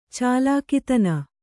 ♪ cālākitana